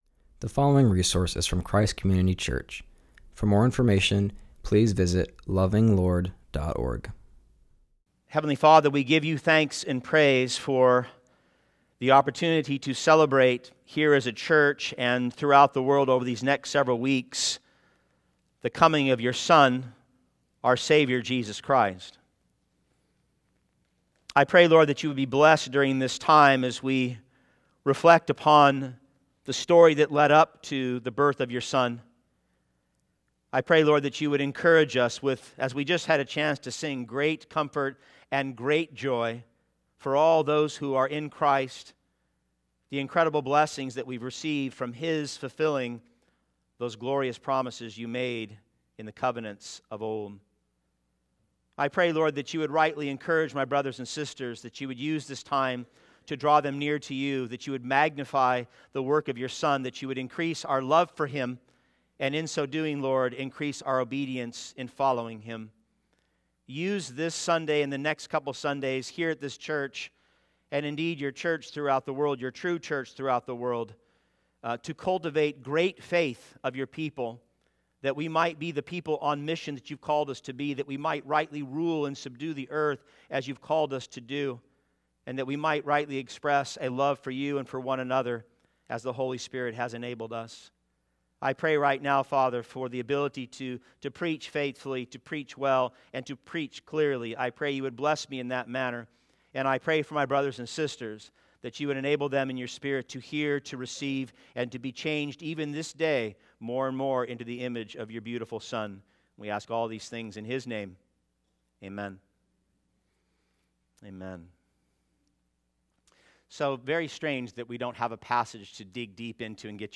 preaches about Christmas Past using various passages.